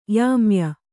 ♪ yāmya